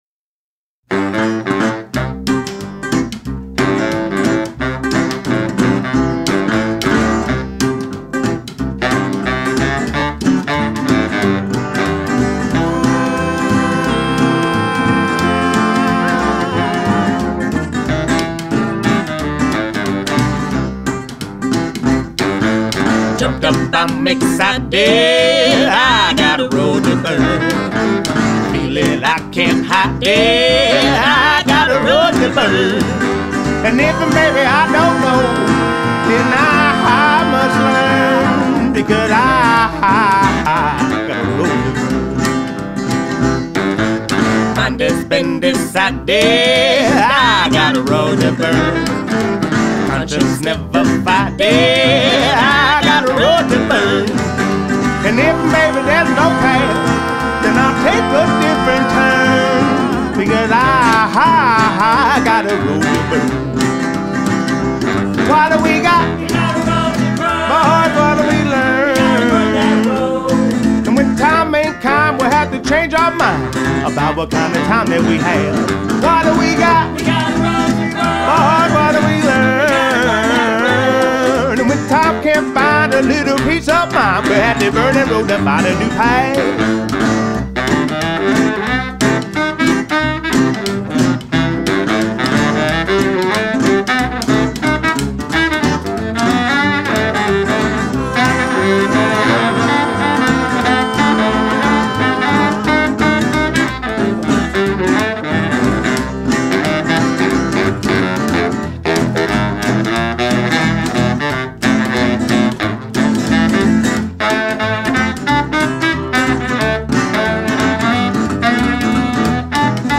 Some old time Americana music influenced by the Swing era.